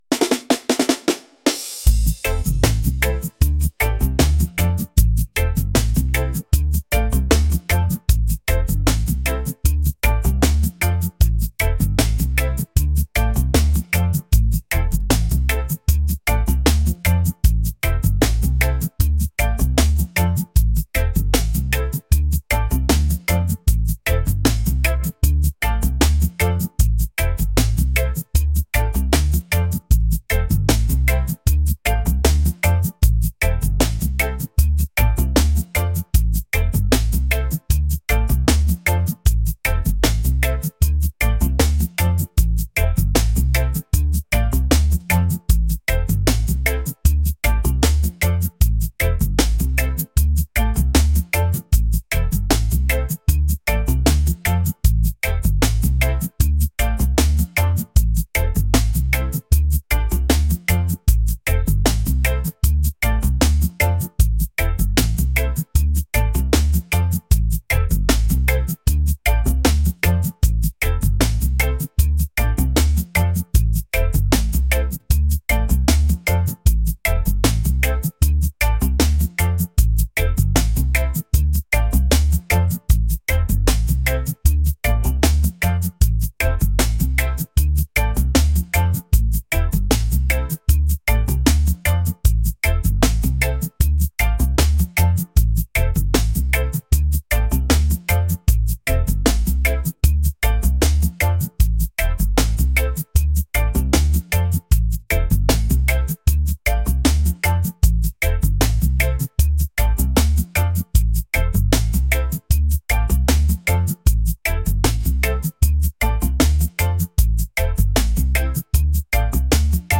smooth | reggae | laid-back